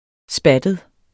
Udtale [ ˈsbadəð ]